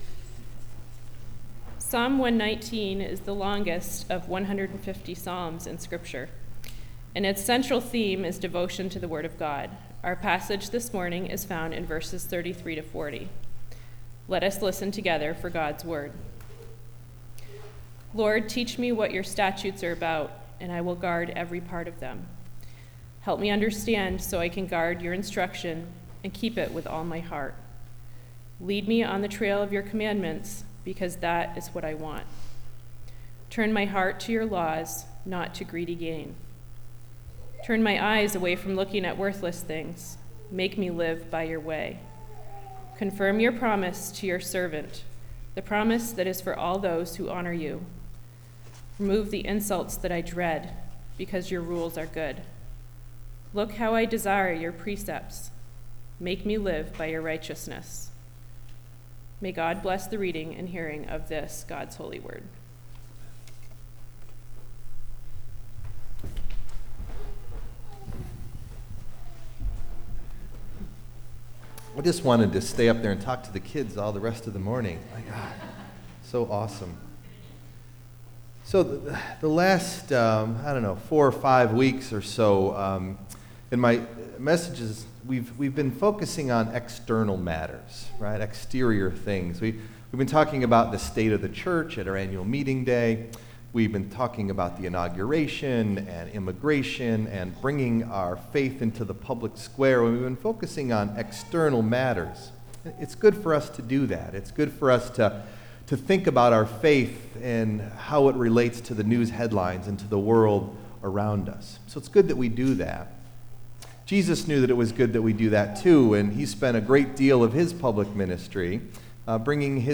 Message Delivered at: The United Church of Underhill (UCC and UMC)